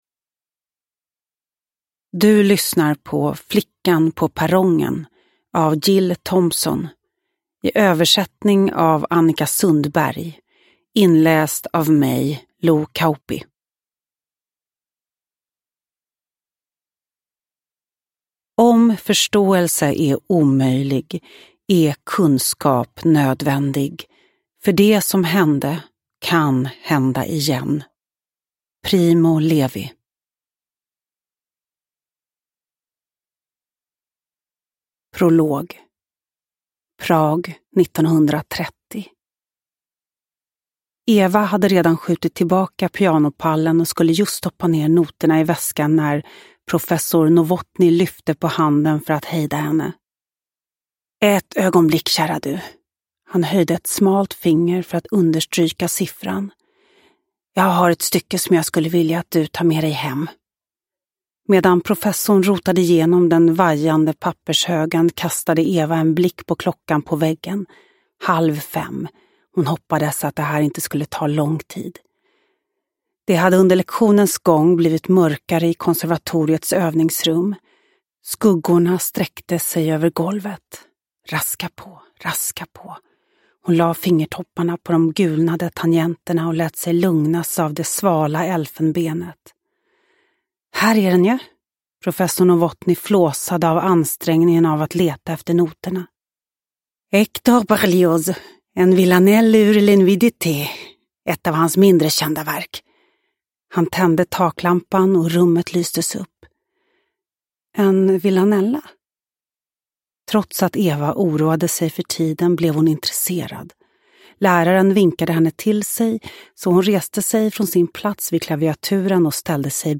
Flickan på perrongen (ljudbok) av Gill Thompson | Bokon